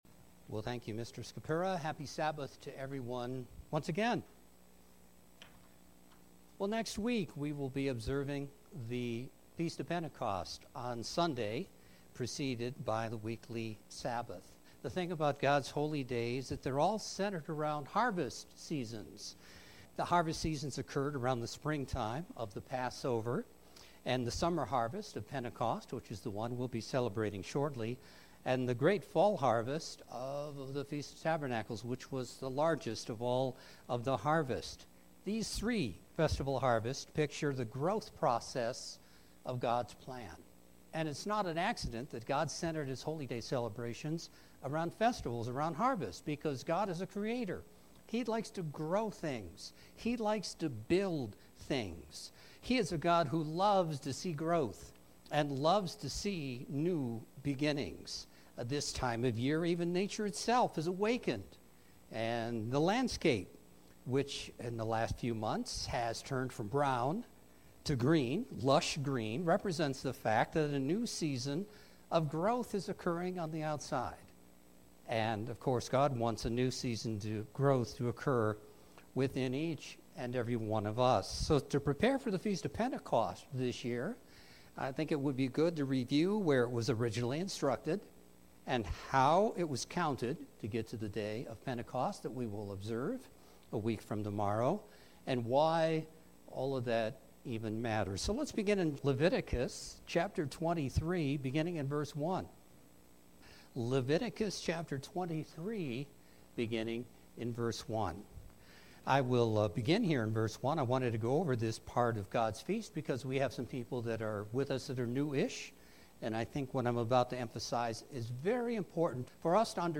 In this Sermon, I want to focus on the “wave sheaf offering” that occurred during the days of Unleavened Bread, the year Jesus died and was resurrected. This ancient “wave sheaf” ritual pictured Jesus Christ not only dying for our sins… but also being resurrected from the dead, and being accepted into heaven as our perfect High Priest.